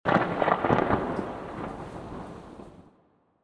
thunder_1.mp3